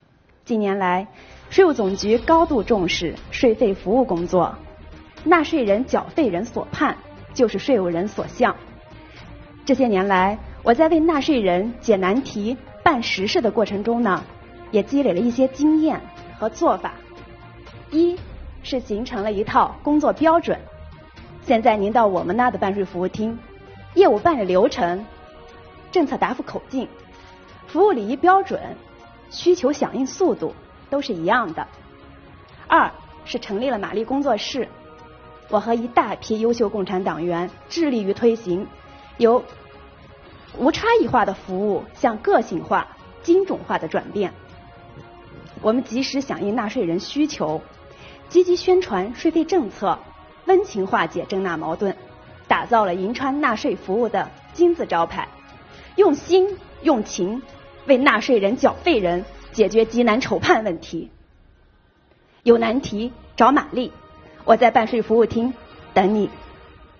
7月15日，中共中央宣传部举行中外记者见面会，邀请5名税务系统党员代表围绕“坚守初心 税收为民”主题与中外记者见面交流。